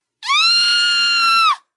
尖叫声 " 尖叫声2
描述：一声短促，高亢的尖叫声。
Tag: 尖叫 尖叫声 恐惧 害怕 尖叫 害怕 害怕